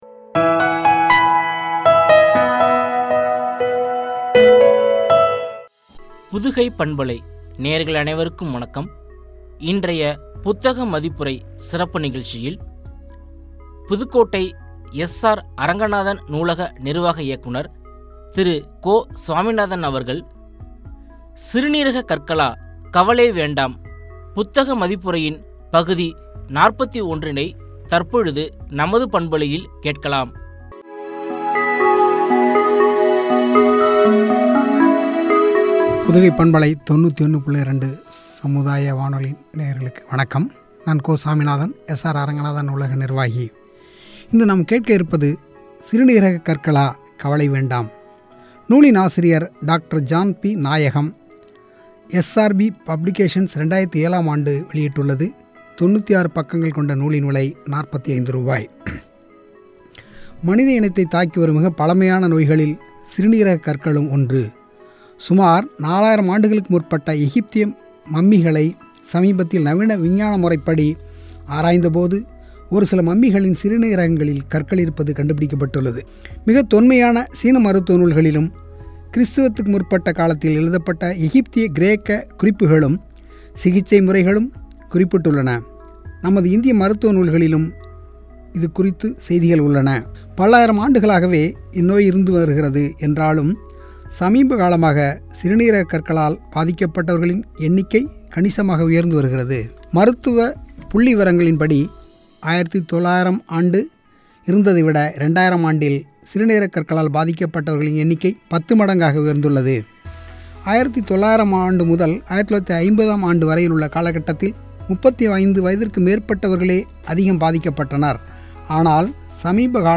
கவலை வேண்டாம்” புத்தக மதிப்புரை (பகுதி – 41), குறித்து வழங்கிய உரையாடல்.